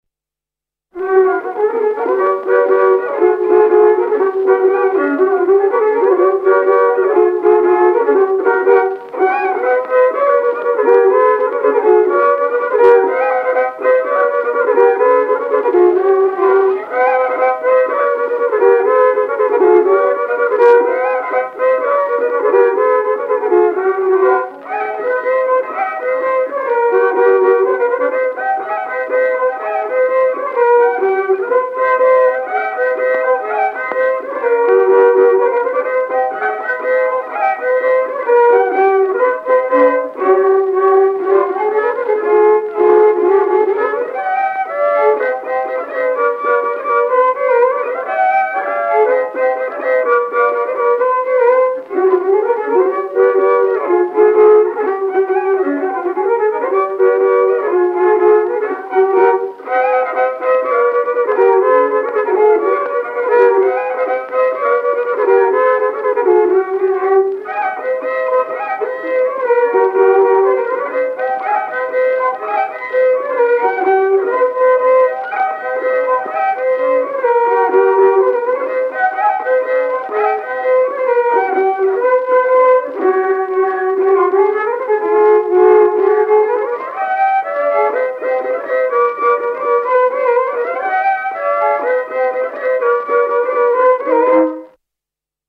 I viiul
II viiul
25 Polka.mp3